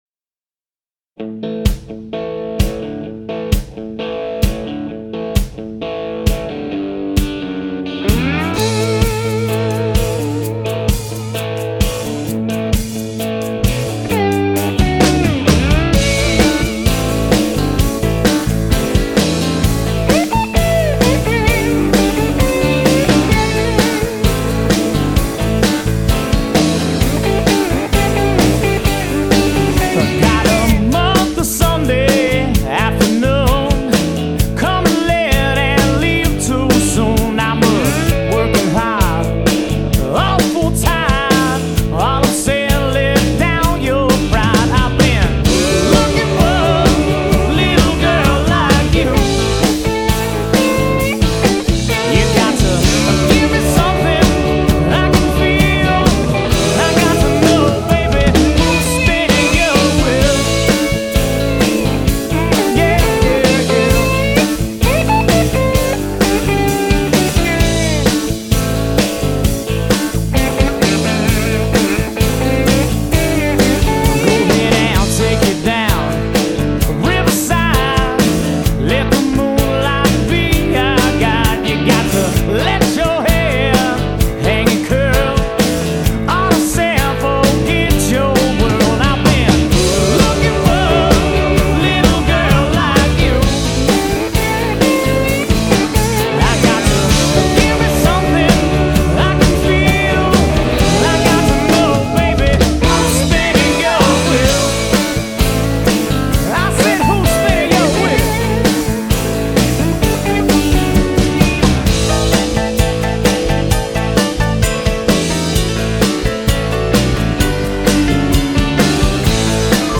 Genre: Blues / Southern Rock
Vocals, Guitar, Harmonica